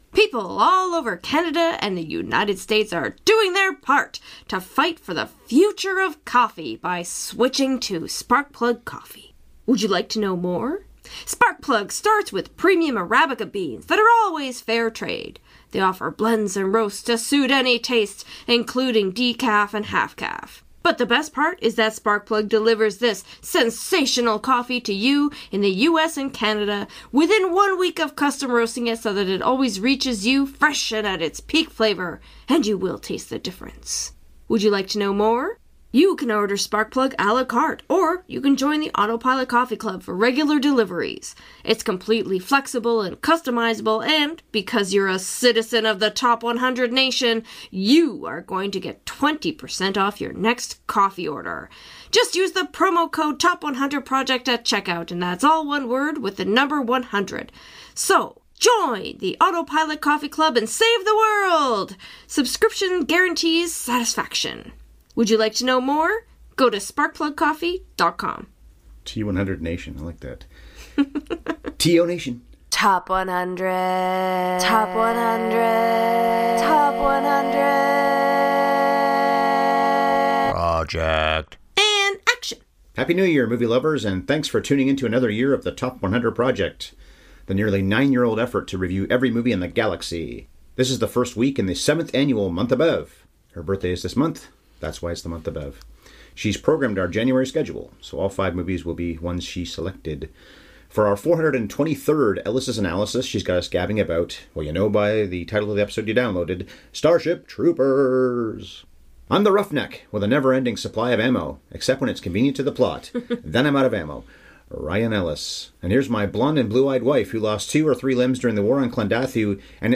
We debated.